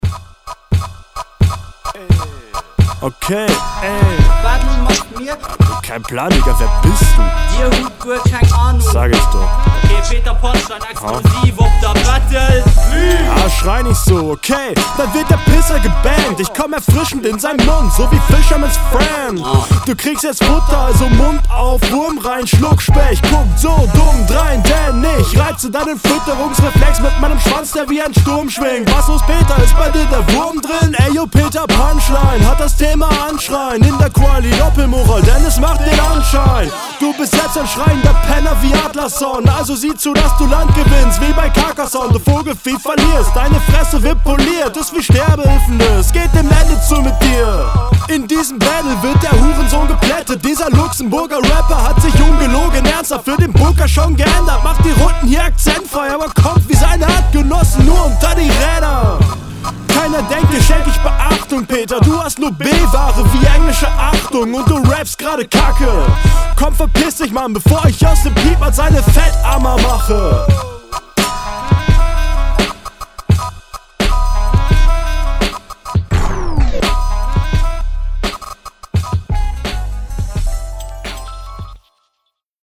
find das hier sehr viel besser als deine rr. Kommst cool über dem Beat und …